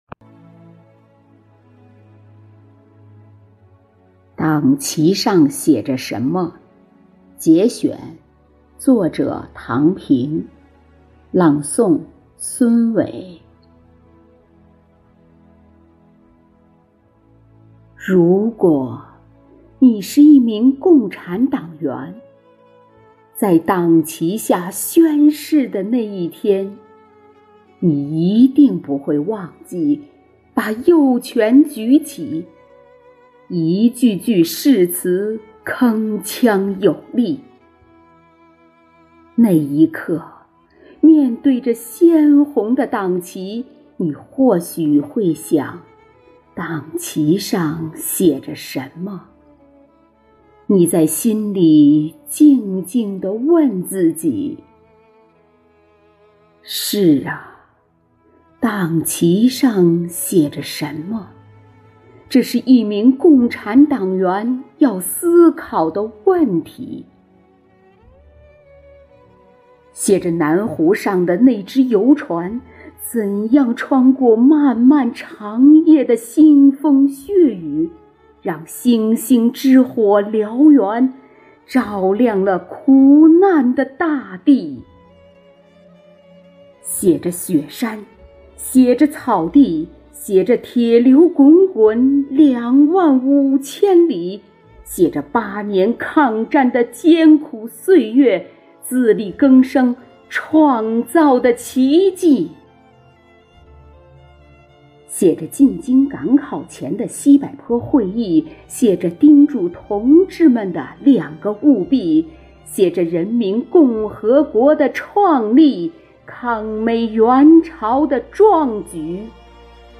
独诵